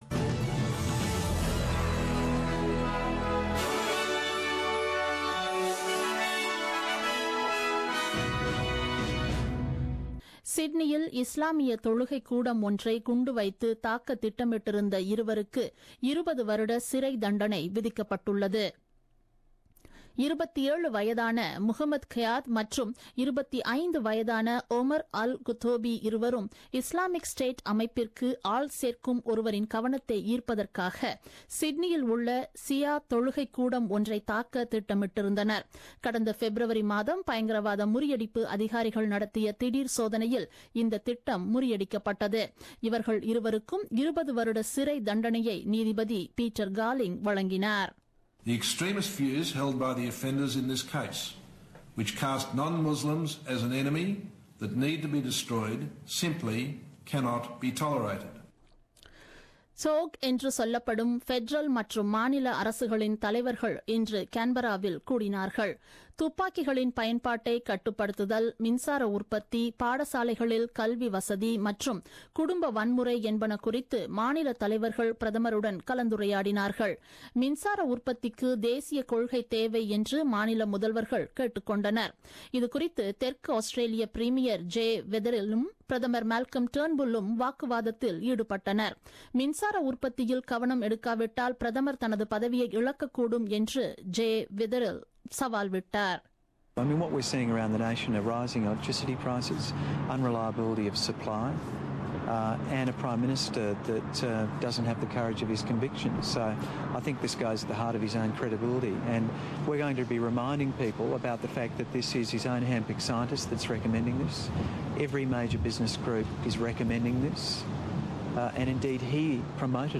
The news bulletin broadcasted on 02 Dec 2016 at 8pm.